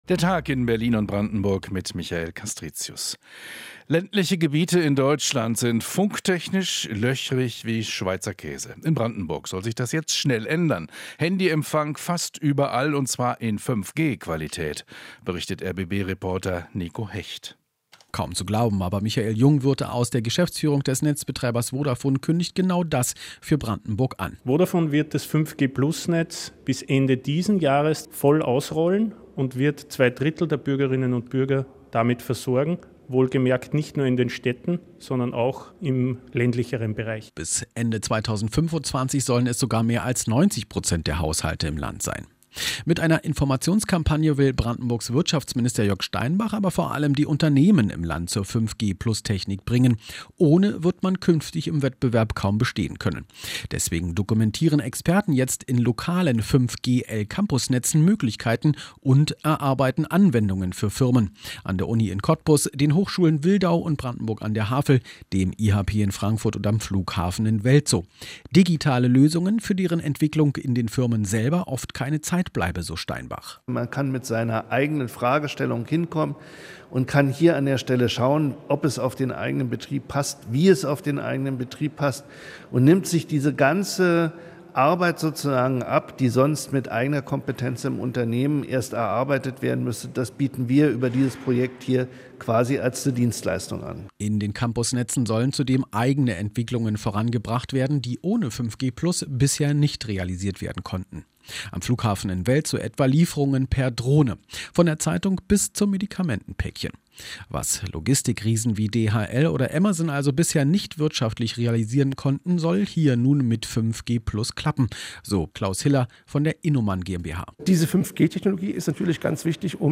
Inforadio Nachrichten, 12.07.2023, 20:30 Uhr - 12.07.2023